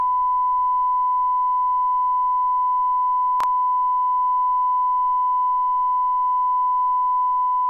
Calib-altoparlante-1m.wav